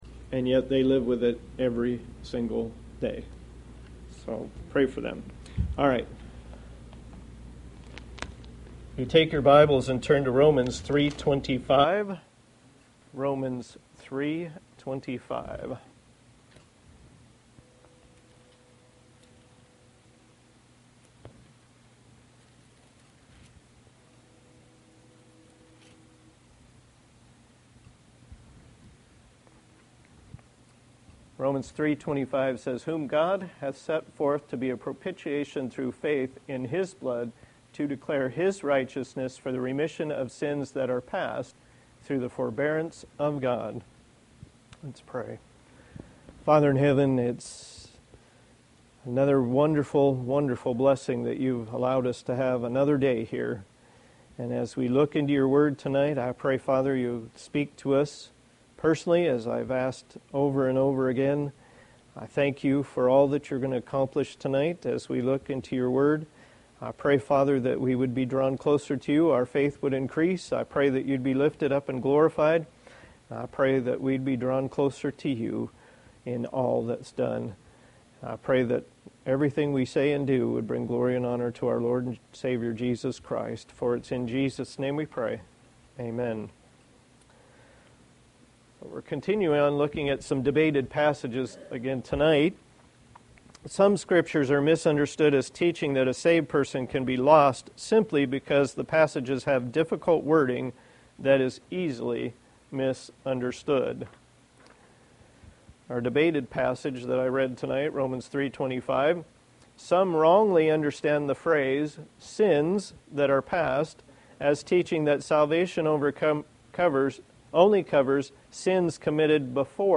Passage: Romans 3:25 Service Type: Thursday Evening